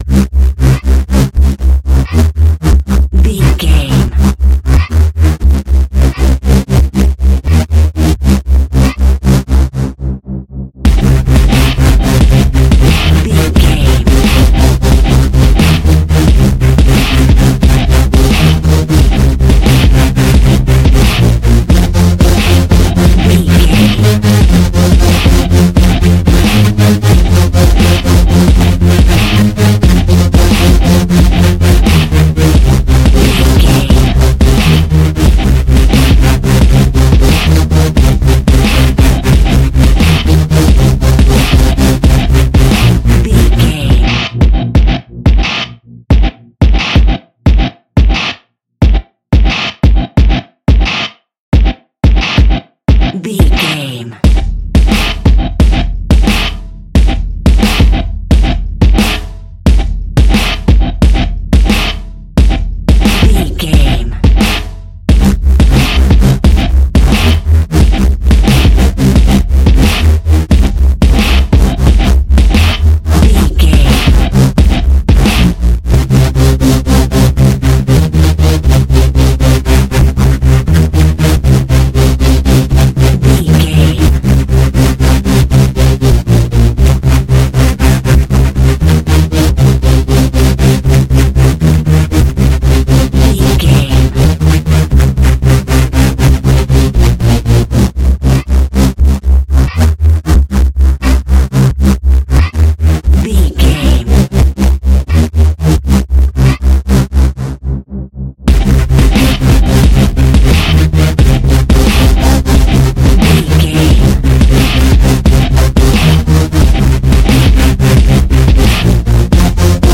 Epic / Action
Fast paced
Aeolian/Minor
aggressive
dark
intense
synthesiser
drum machine
breakbeat
energetic
synth leads
synth bass